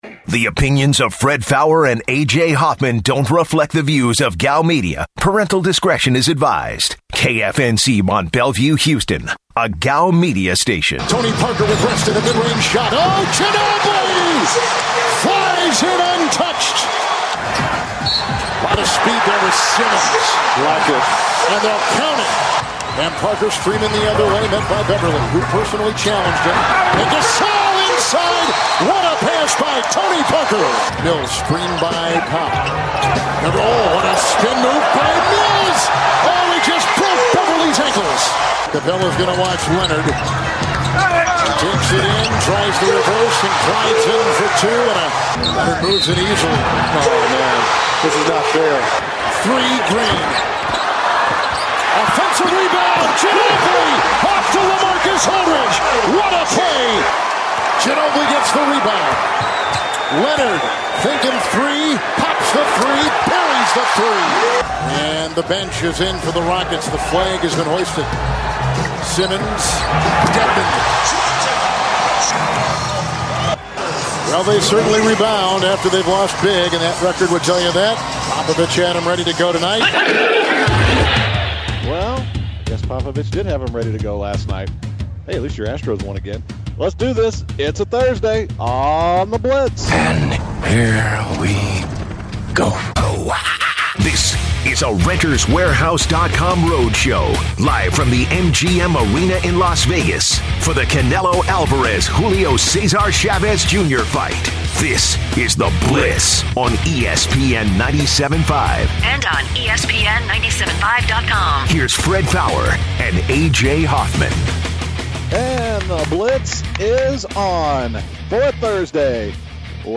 live from Radio Row in Las Vegas for the Canelo/Chavez Duffle Bag.